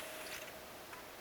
erikoinen ääni,
erikoinen_aani_mika_se_on.mp3